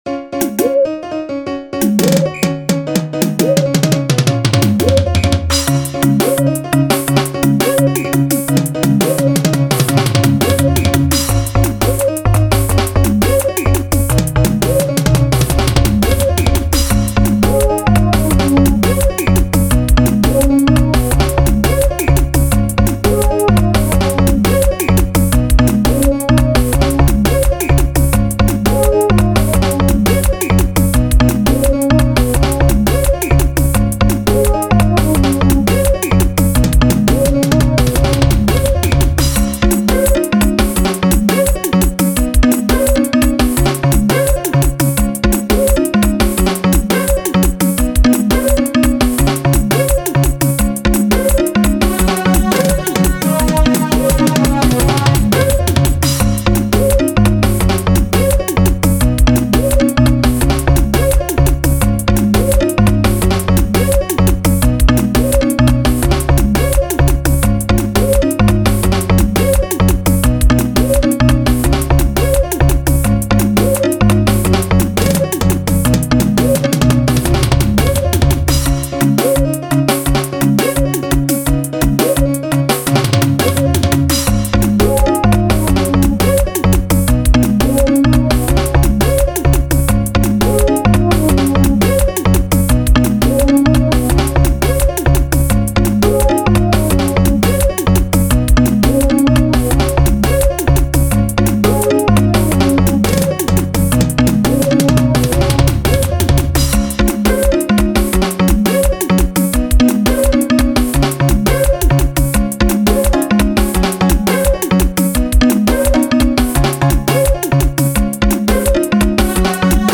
05:15 Genre : Xitsonga Size